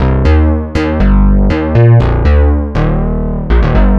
lead-bass.wav